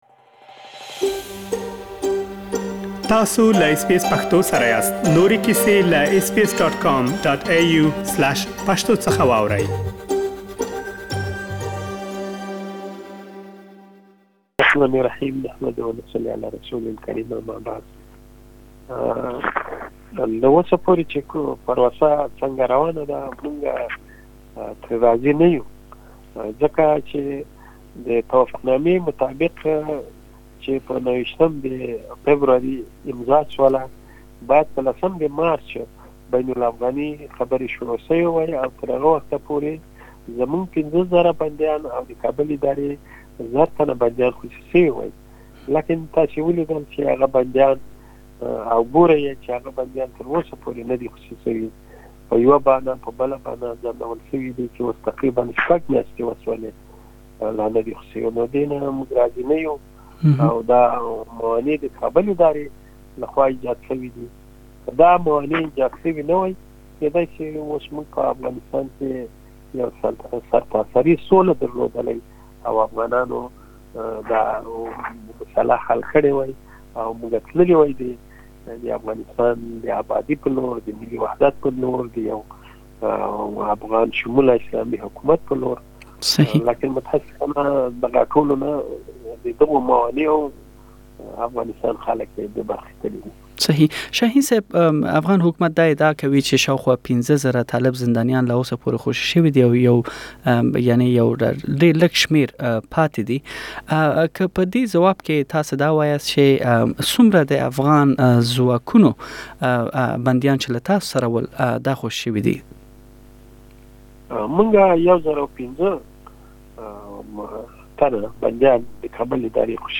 د طالبانو له وياند سهيل شاهين سره ځانګړې مرکه